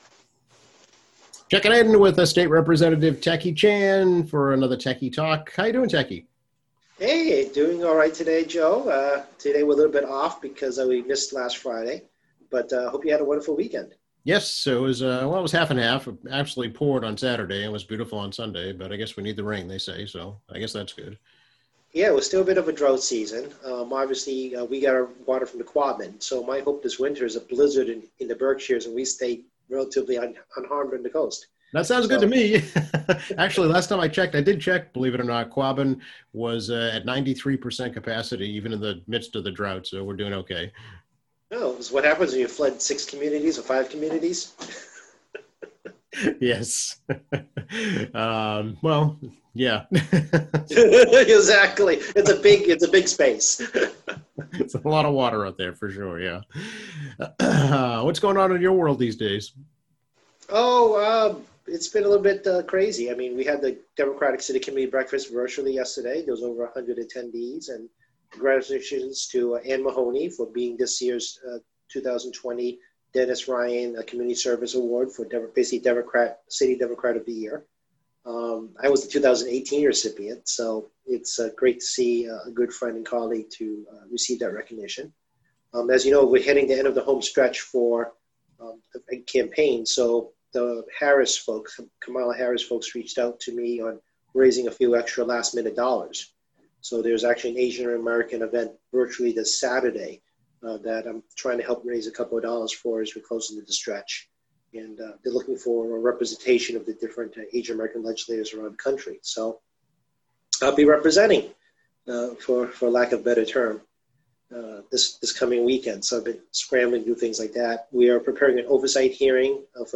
State Representative Tackey Chan, D - MA 2nd Norfolk District chats about the Governor's budget proposal, the eviction moratorium, and how the second wave of the coronavirus will impact the economy.